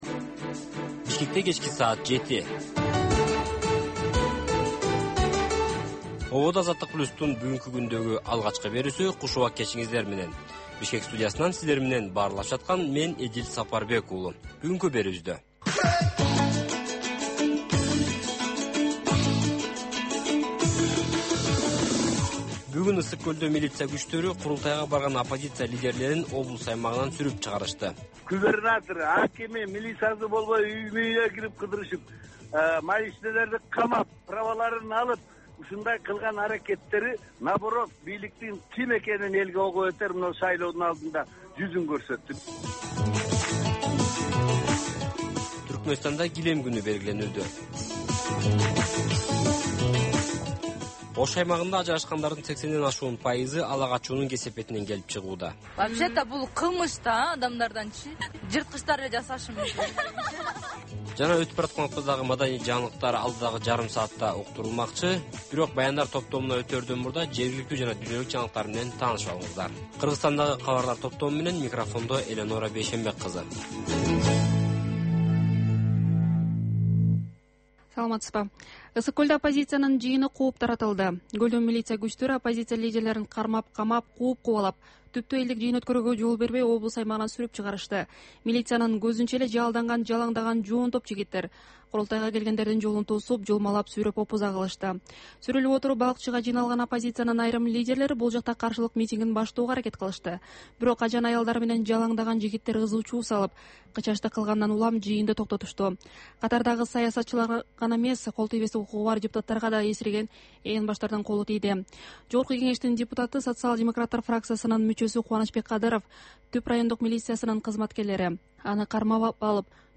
"Азаттык үналгысынын" кыргызстандык жаштарга арналган бул кечки алгачкы үналгы берүүсү жергиликтүү жана эл аралык кабарлардан, репортаж, маек, баян жана башка берүүлөрдөн турат.